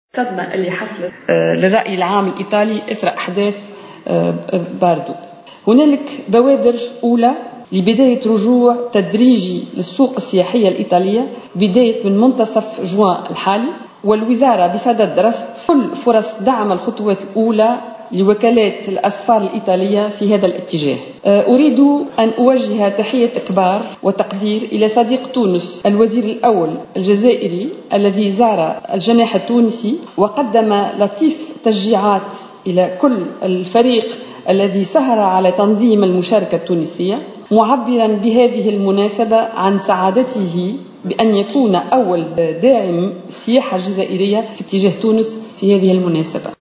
وأضافت في ندوة صحفية انعقدت اليوم بمقر الحكومة بالقصبة أن مشاركة تونس بتظاهرة ميلانو 2015 مثّل فرصة لتوجيه رسالة طمأنة لوكالات الأسفار الايطالية والعالمية خاصة بعد حادثة باردو الارهابية في مارس الماضي.